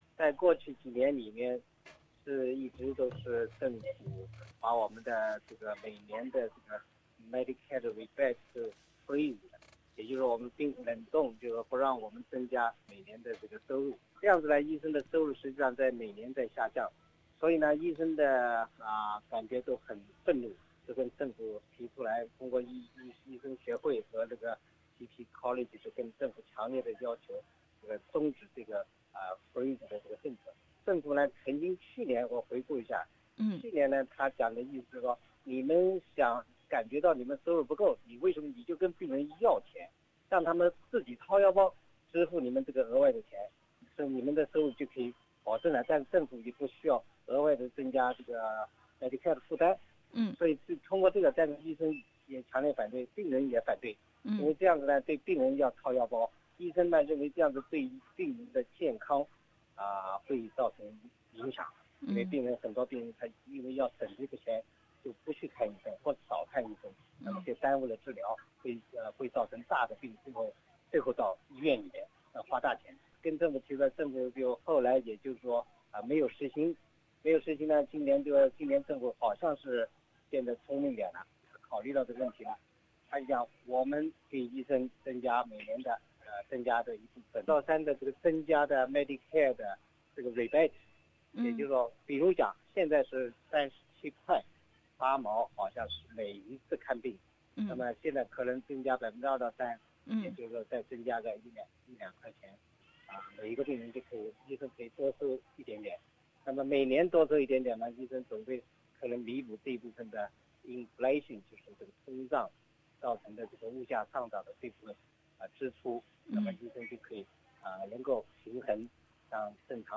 他接受本台采访，详解上述的一些措施对病人和医生各自意味着什么。